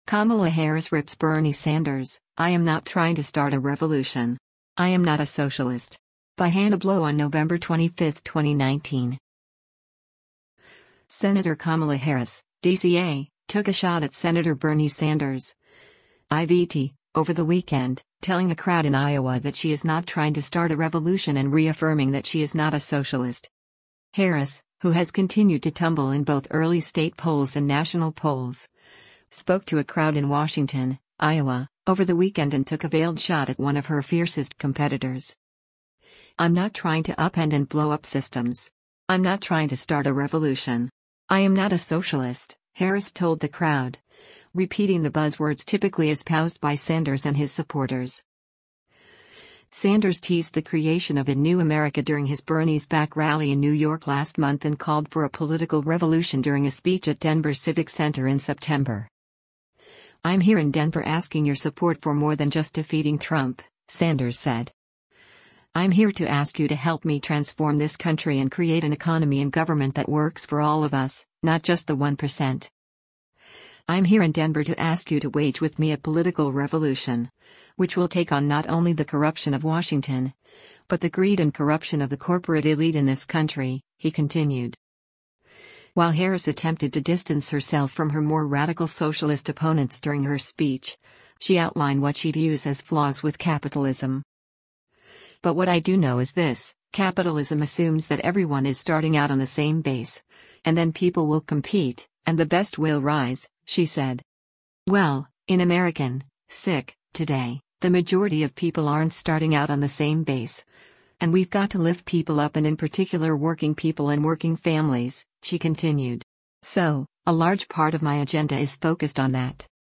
Harris, who has continued to tumble in both early state polls and national polls, spoke to a crowd in Washington, Iowa, over the weekend and took a veiled shot at one of her fiercest competitors.